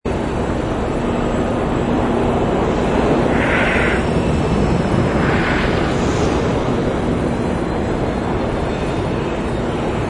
ambience_cityscape_light.wav